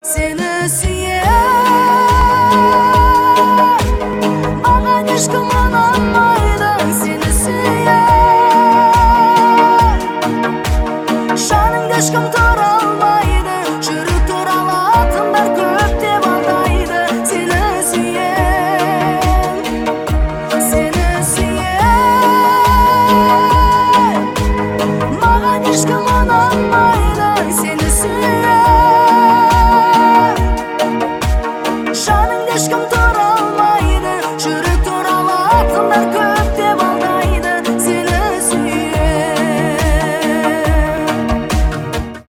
шансон , cover , поп